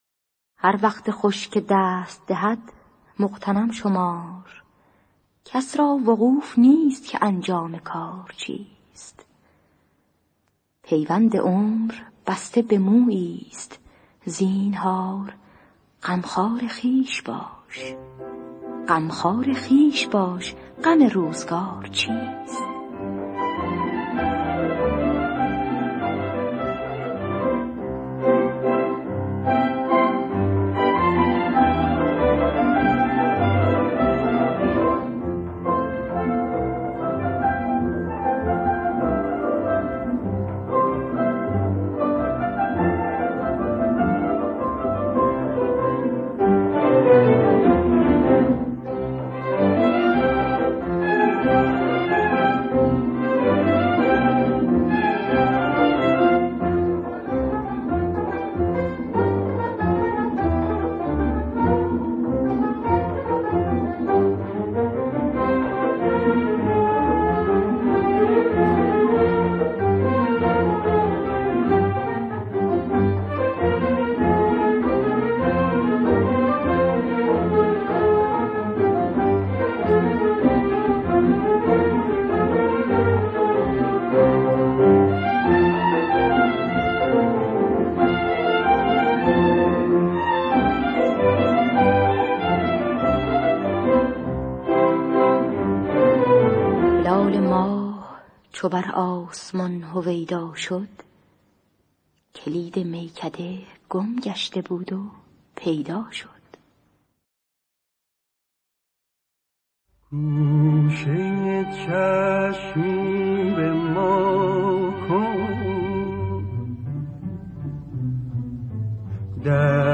گلهای رنگارنگ ۳۳۸ - ماهور